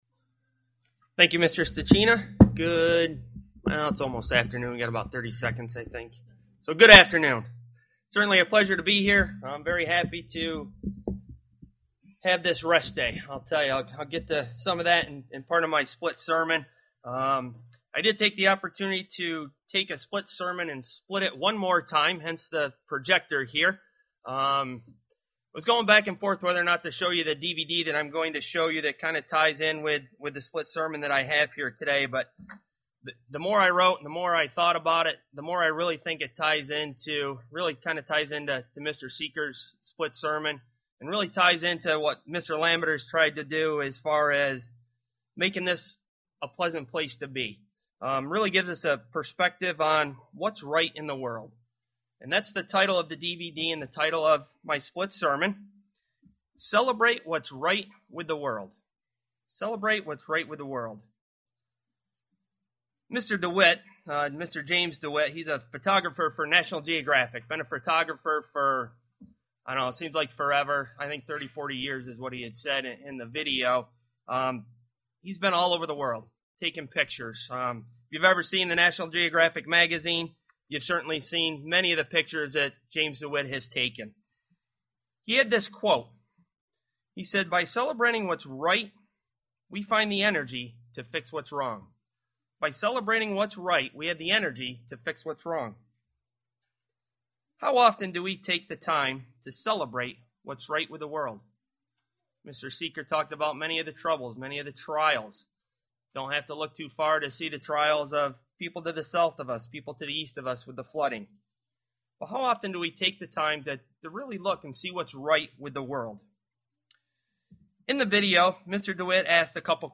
Given in Elmira, NY
Print See the possibilities of what is right in the world that follows God's way UCG Sermon Studying the bible?